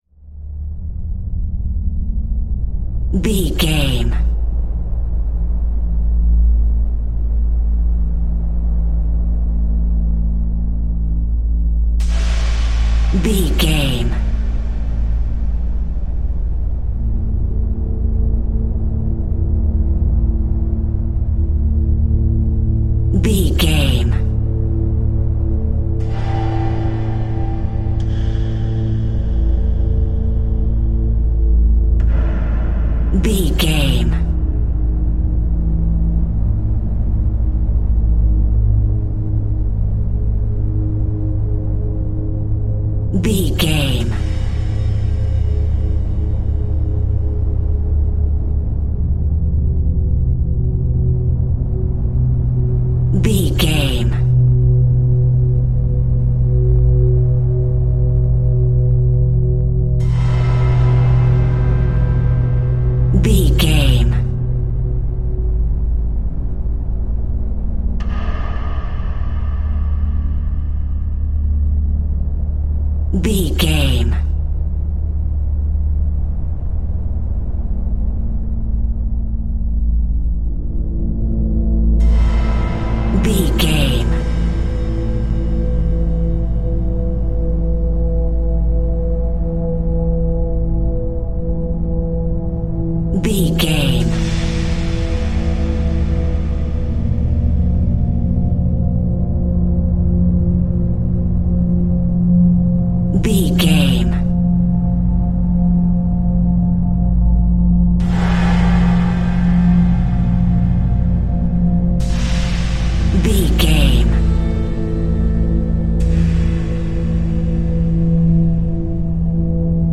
Atonal
ominous
dark
suspense
eerie
instrumentals
horror music
Horror Pads
horror piano
Horror Synths